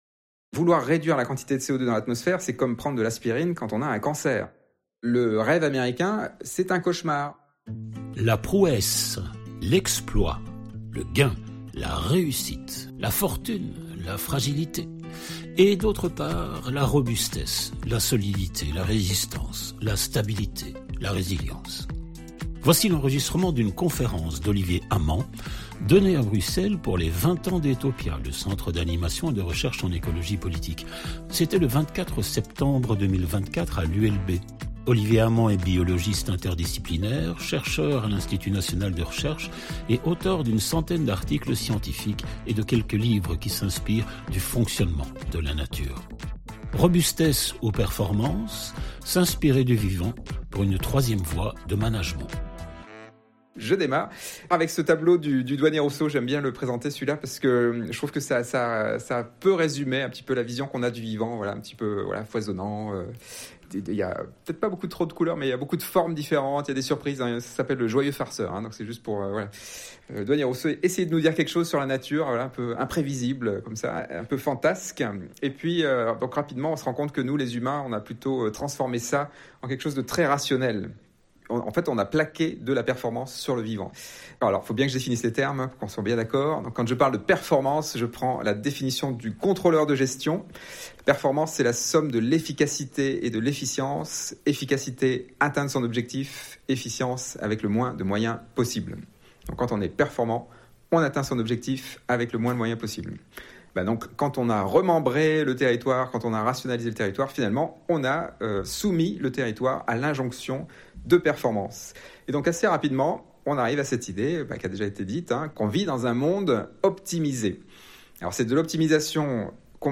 Conférences